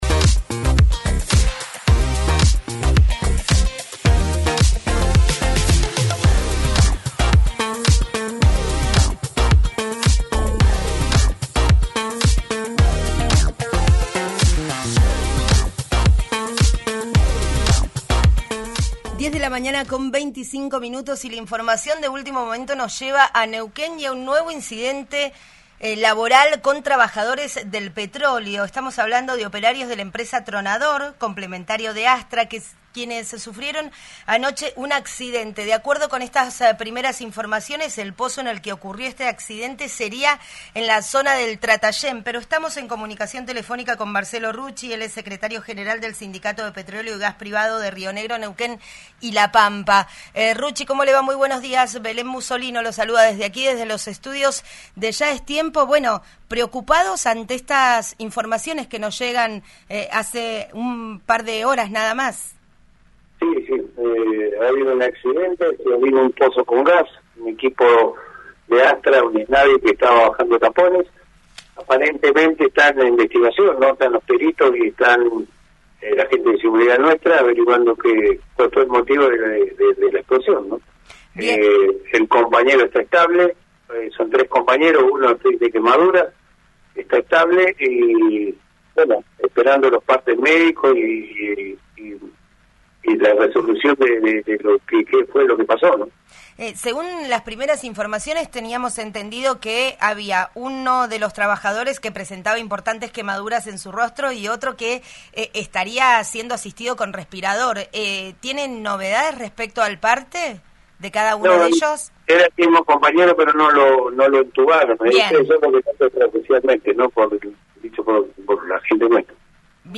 Escuchá al dirigente gremial